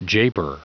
Prononciation du mot japer en anglais (fichier audio)
Prononciation du mot : japer